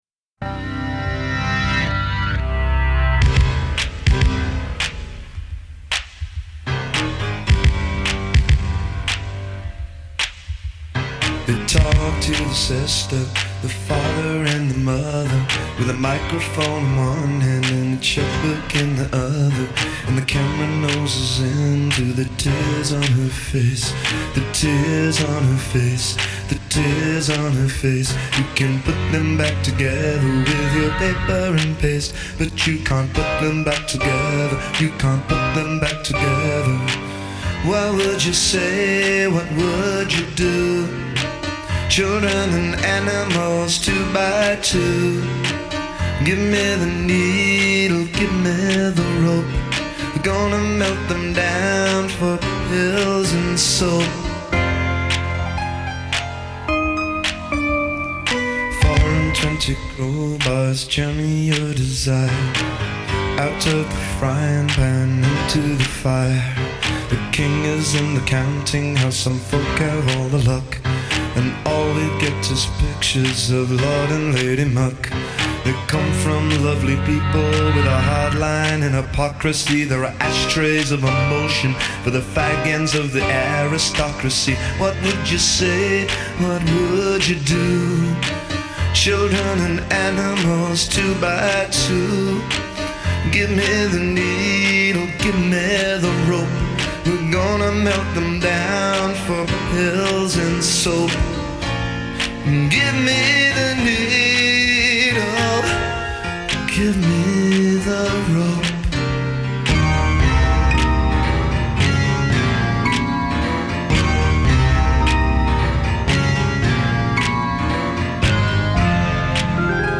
drum machine
piano